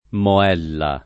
moella [ mo $ lla ] s. f.